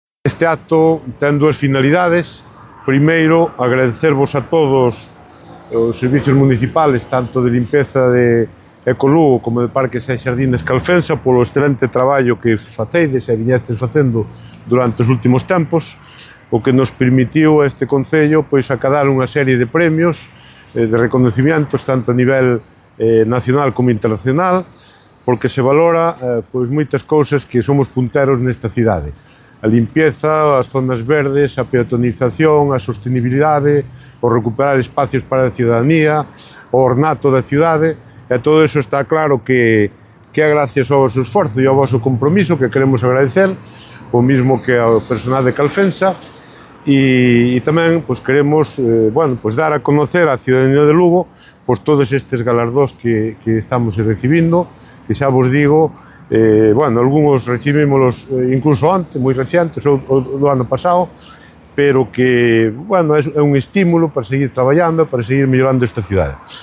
El alcalde de Lugo, Miguel Fernández, reconoció este viernes la labor del personal del servicio municipal de limpieza, LuceLugo, y del servicio de Parques y Jardines, durante un acto de homenaje por su contribución decisiva a que la ciudad se consolidase como un referente nacional e internacional en el ámbito de la limpieza, la sostenibilidad, el reciclaje y el ornato público.